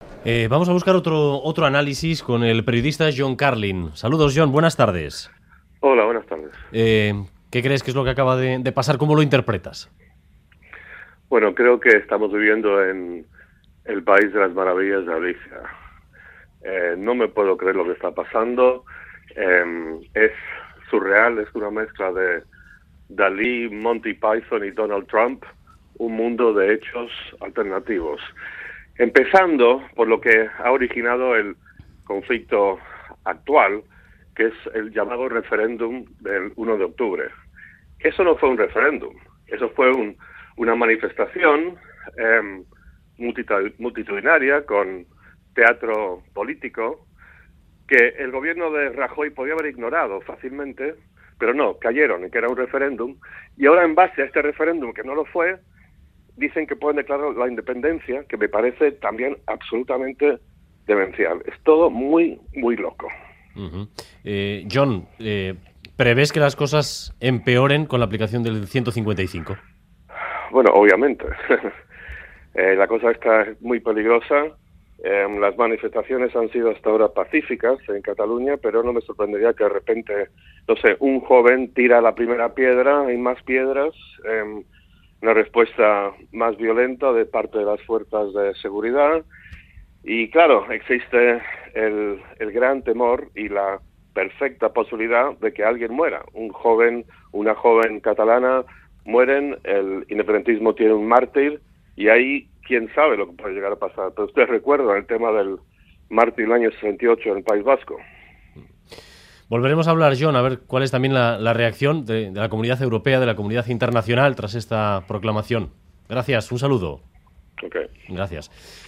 Audio: John Carlin, periodista británico opina que 'Estamos viviendo en el país de las maravillas de Alicia, no me puedo creer lo que está pasando'.